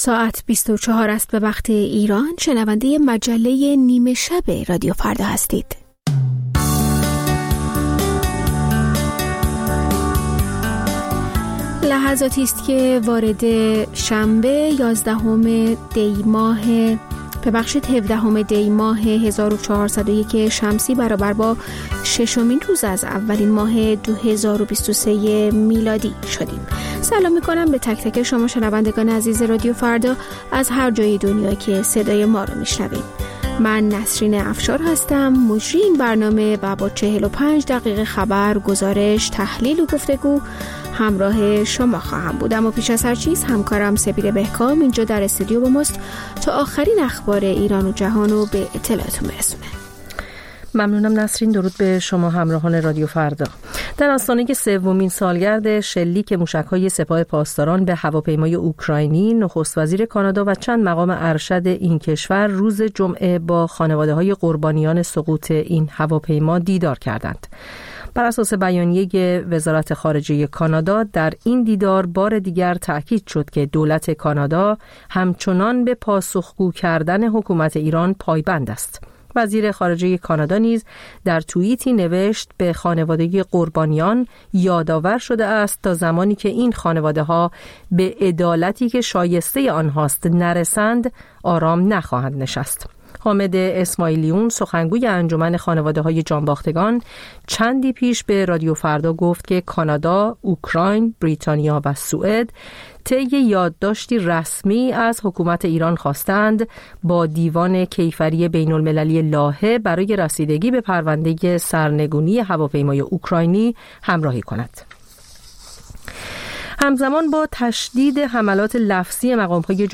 همچون هر روز، مجله نیمه‌شب رادیو فردا، تازه‌ترین خبرها و مهم‌ترین گزارش‌ها را به گوش شما می‌رساند.